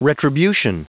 Prononciation du mot retribution en anglais (fichier audio)
Prononciation du mot : retribution